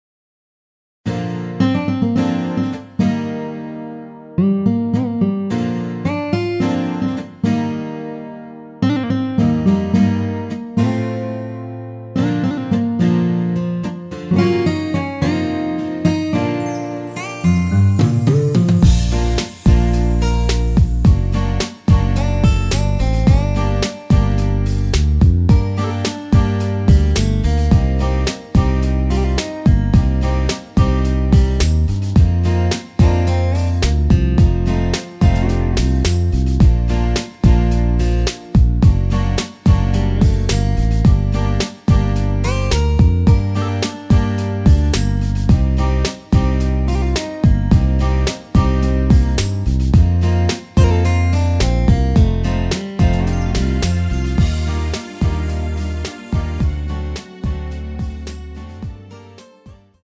Bb
◈ 곡명 옆 (-1)은 반음 내림, (+1)은 반음 올림 입니다.
앞부분30초, 뒷부분30초씩 편집해서 올려 드리고 있습니다.